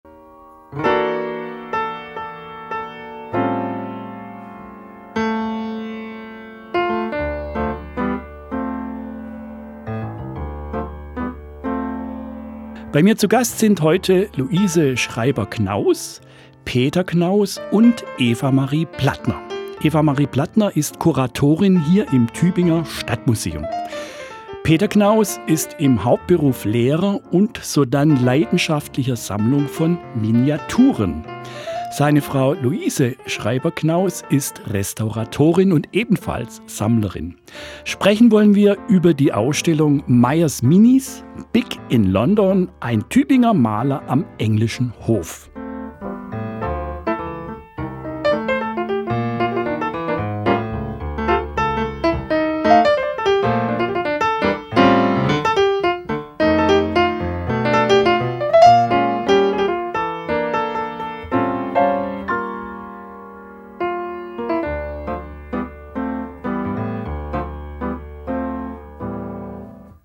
„Studiogespräch über die Ausstellung Meyers Minis im Tübinger Stadtmuseum“ (613)